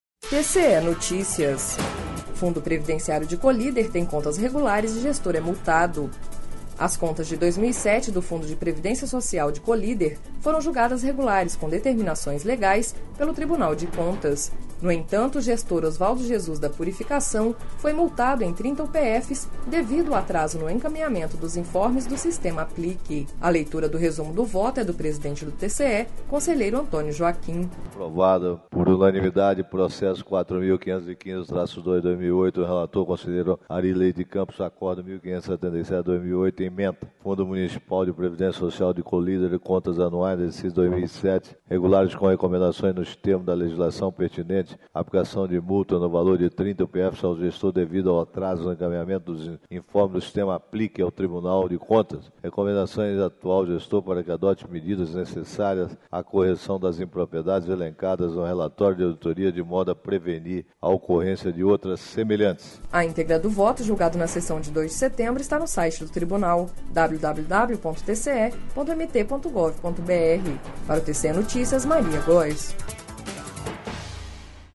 A leitura do resumo do voto é do presidente do TCE, conselheiro Antonio Joaquim.